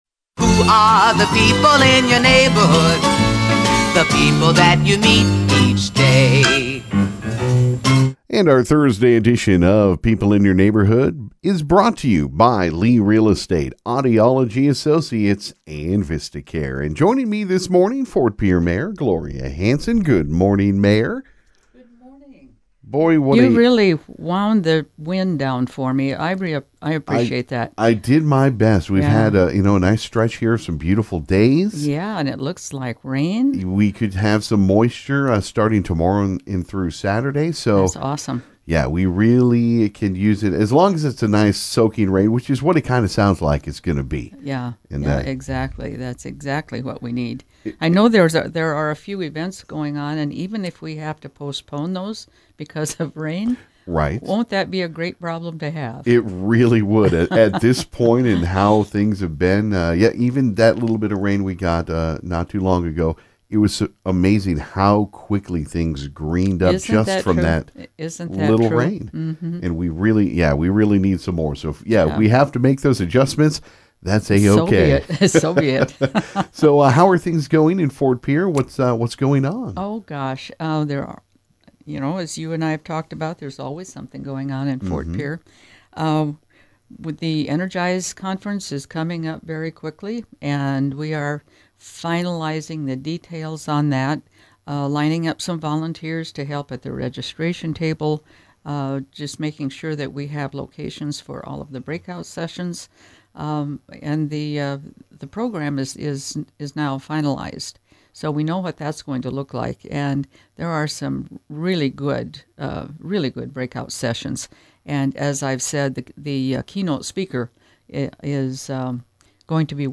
This morning on KGFX, Ft. Pierre Mayor Gloria Hanson made her weekly visit to talk about what’s going on. She talked about the Bad River Cannabis ground breaking, teased a new business coming soon and also talked about some summer job opportunities in Ft. Pierre.